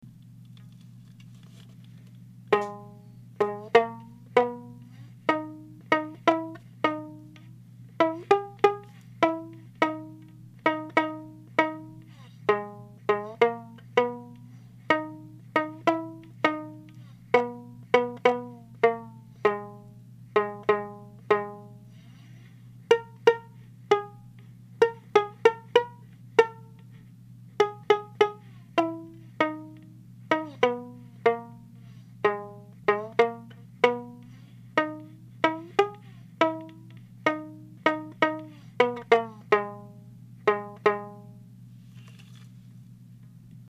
テグスや菜箸など、材料費が少々と、作る手間が少々かかりますが、かけただけのことはある音が出ます。